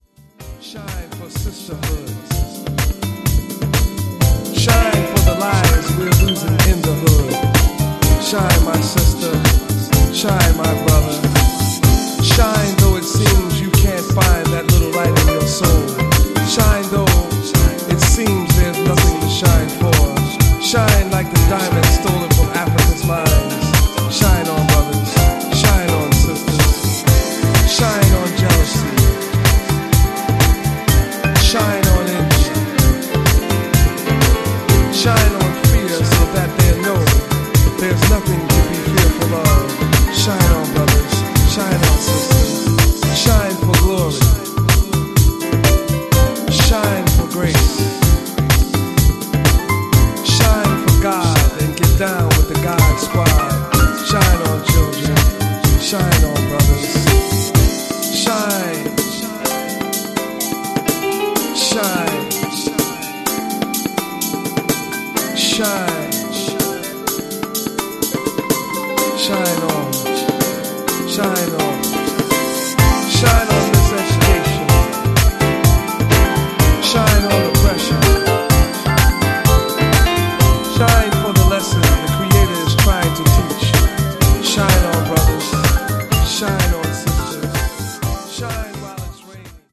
deep house